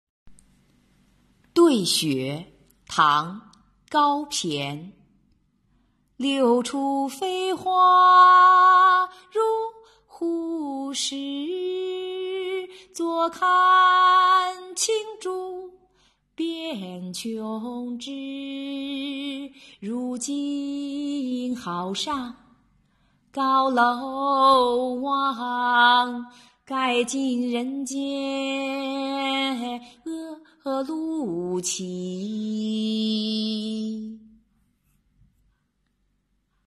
对雪—古诗吟诵